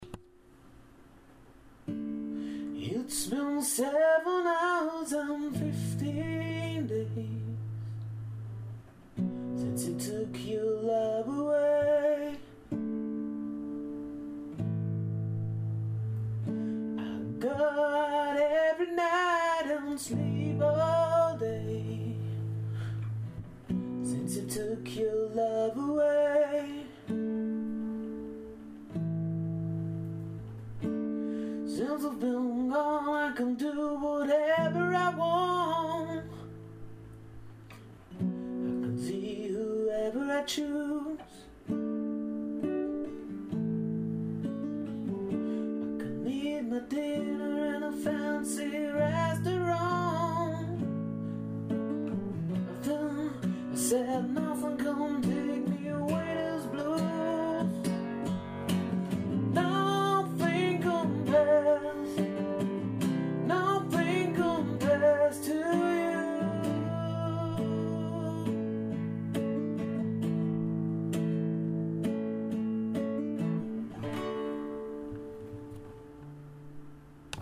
1 Stimme, eine Gitarre
schöner Sound im kleinen Rahmen
• Unplugged